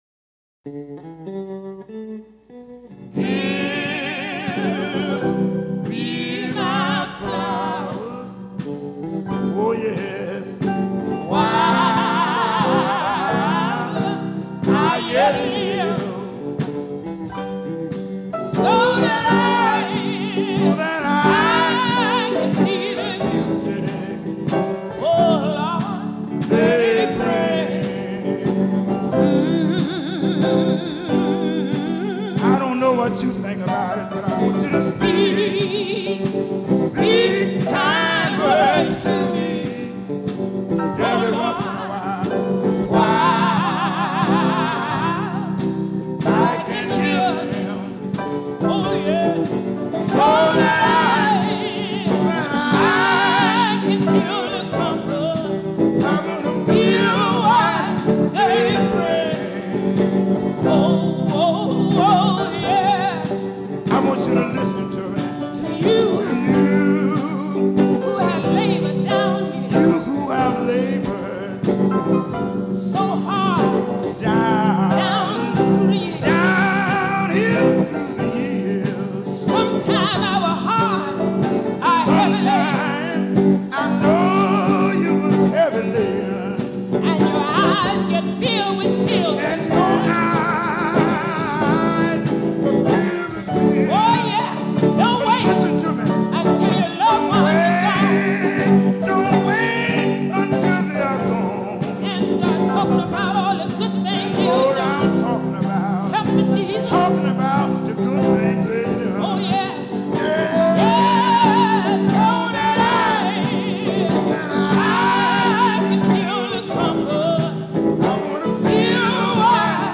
” sang by the gospel group